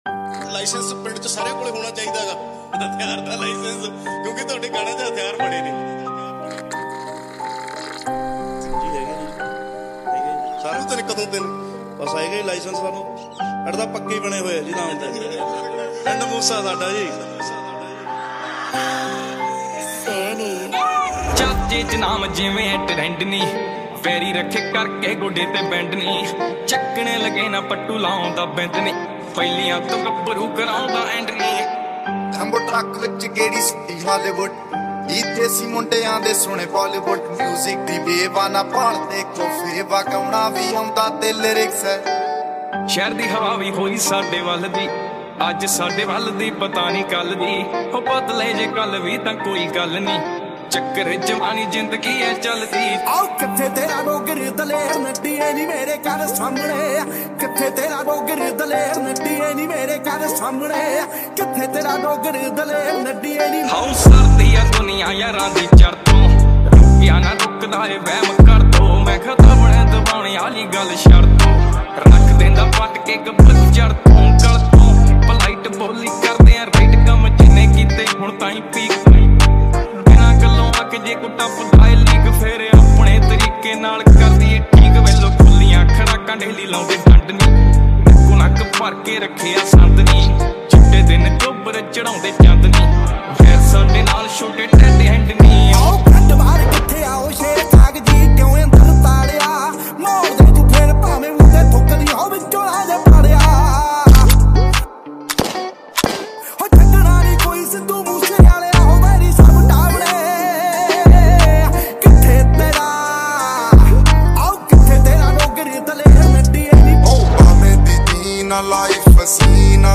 Punjabi song
vocals